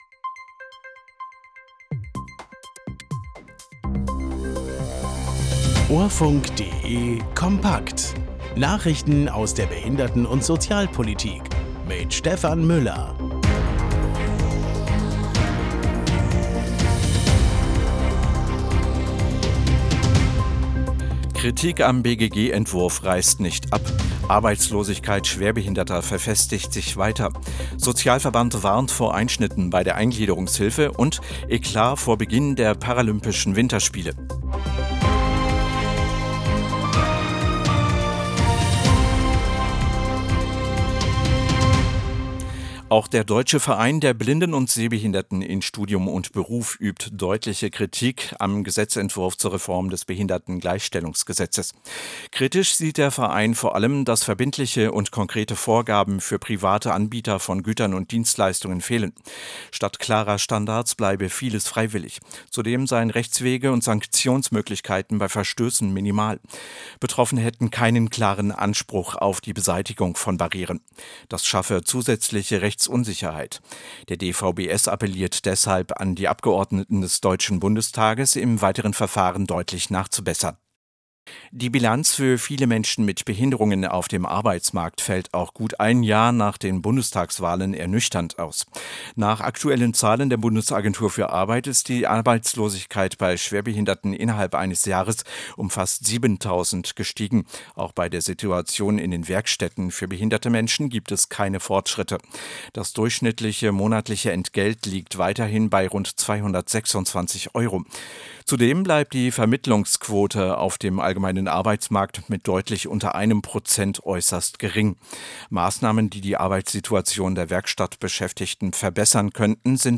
Nachrichten aus der Behinderten- und Sozialpolitik vom 04.03.2026